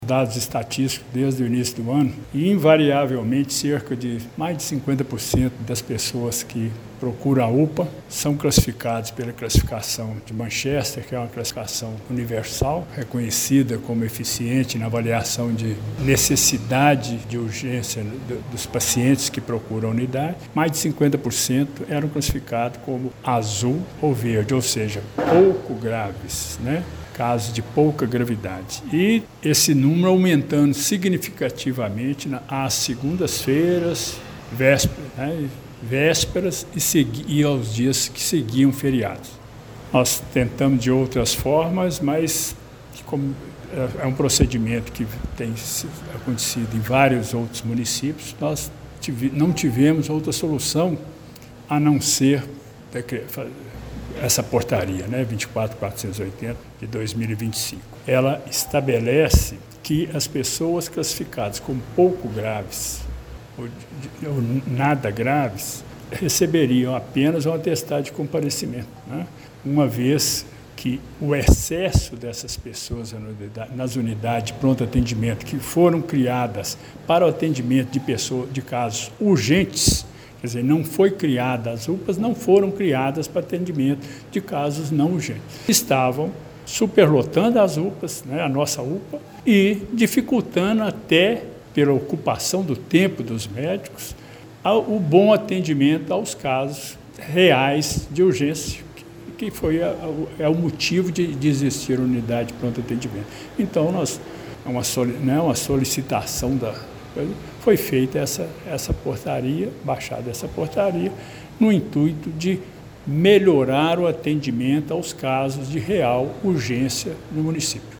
Restrição na UPA: secretário de Saúde explica por que atestado médico será emitido somente para casos graves em Pará de Minas